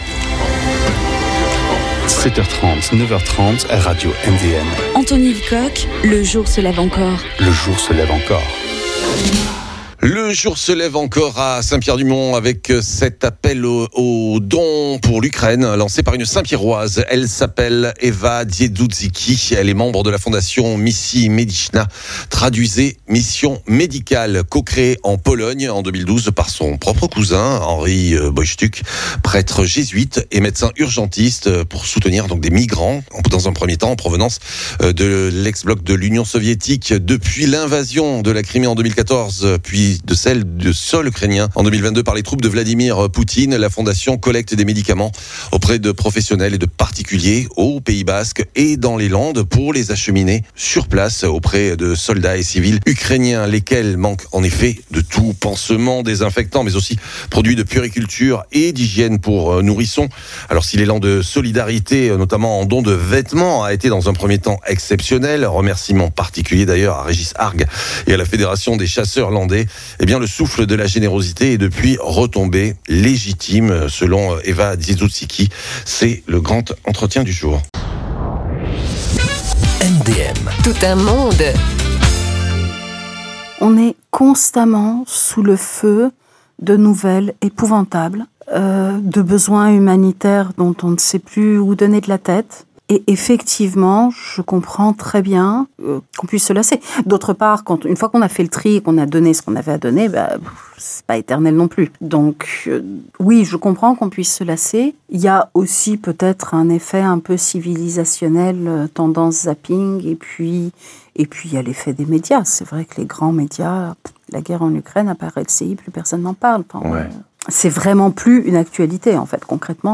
Témoignage.